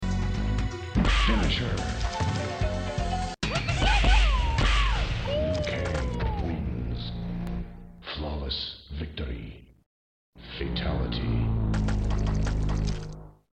Mortal Kombat II 1993 Arcade Sound Effects Free Download